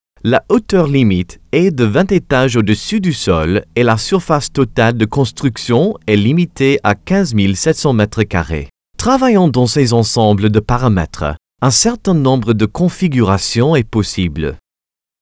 COOL,REAL,FRIENDLY,SMART,POWERFUL ,FRENCH,ENGLISH,YOUNG,VOICE OVER
middle west
Sprechprobe: Sonstiges (Muttersprache):